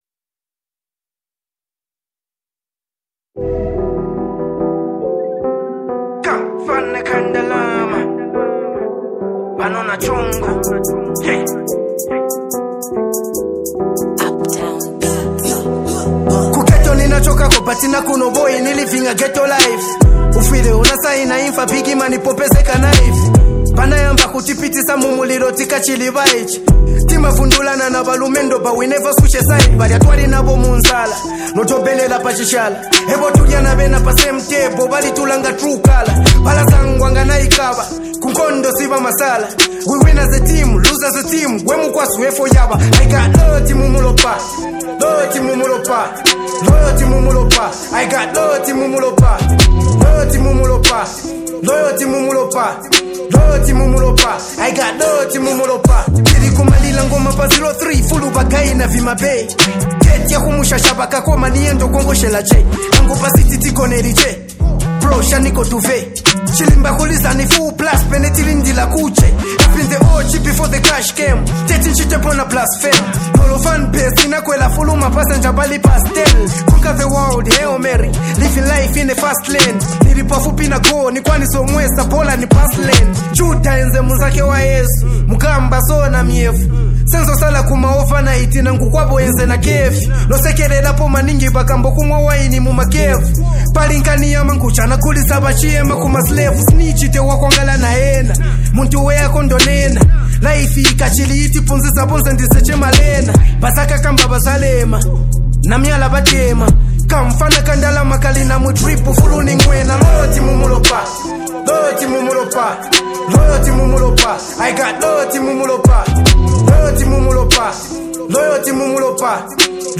smooth vocals add an emotional touch to the track